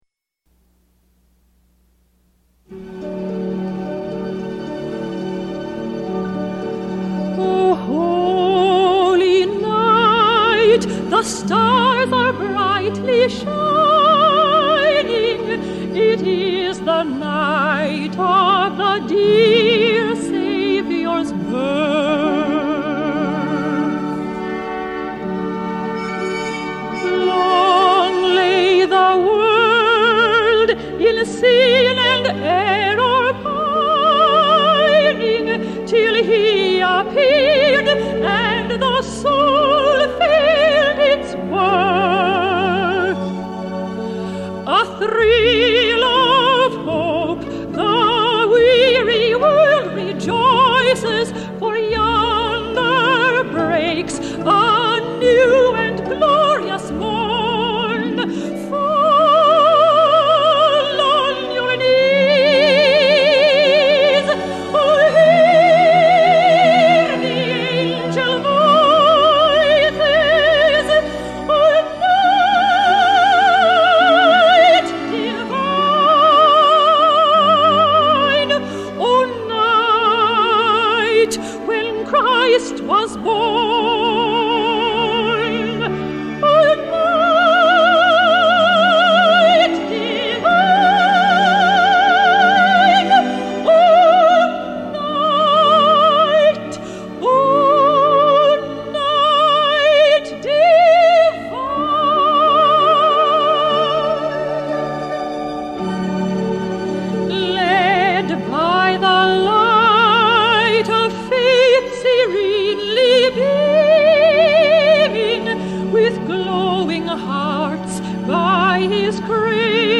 Item Category / Media Format: 33 rpm LP Records
Music Genre: Christmas Music